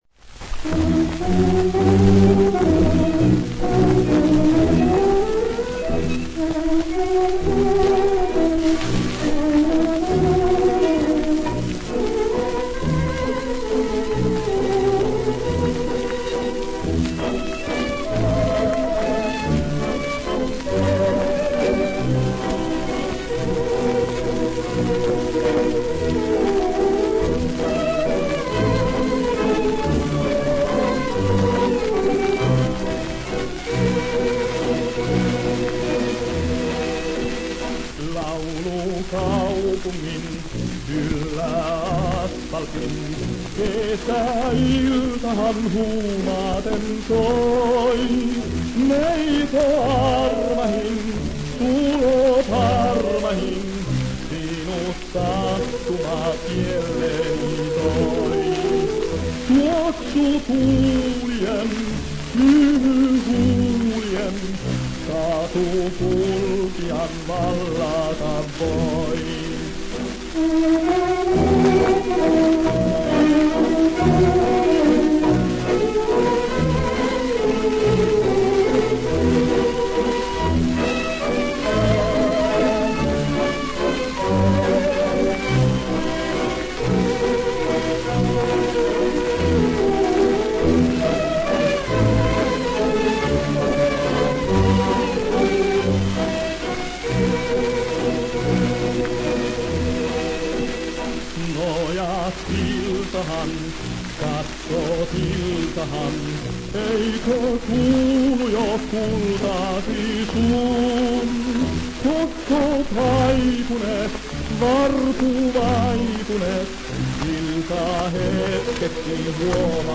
Вальс
Грустный лирический вальс…